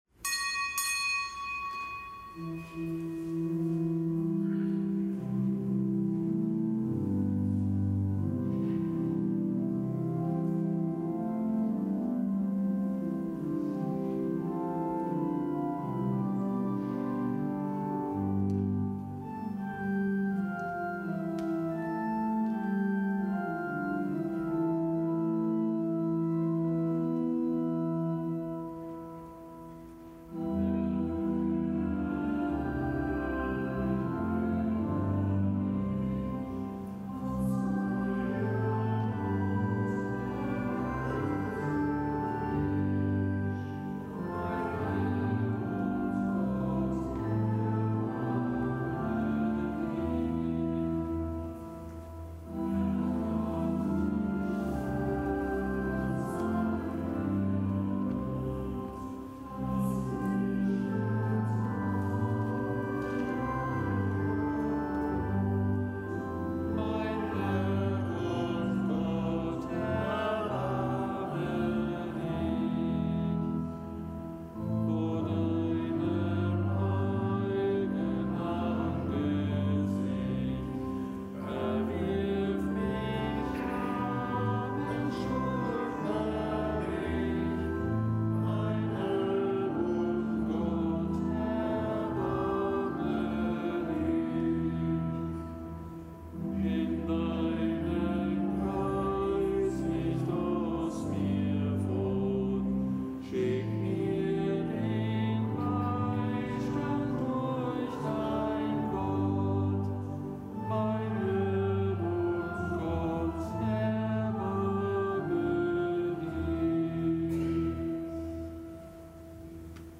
Kapitelsmesse aus dem Kölner Dom am Donnerstag der dritten Fastenwoche. Zelebrant: Weihbischof Dominikus Schwaderlapp.